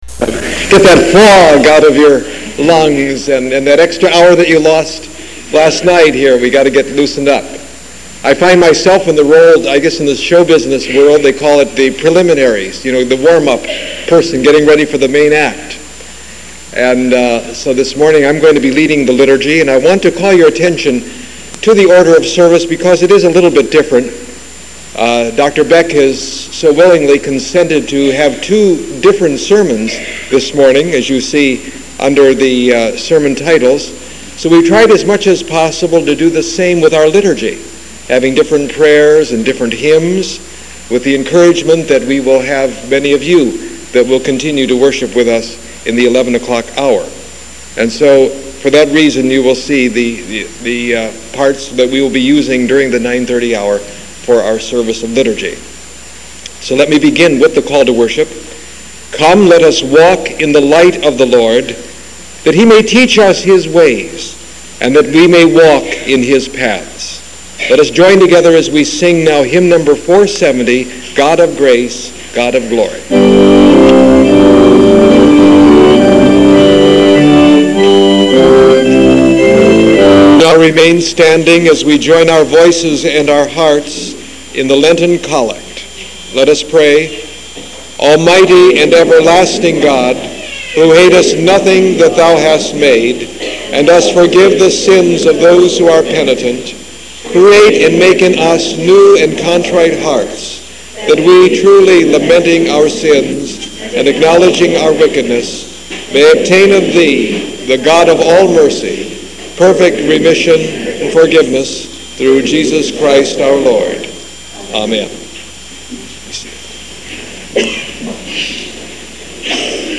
A lecture